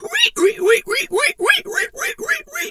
pig_2_hog_seq_01.wav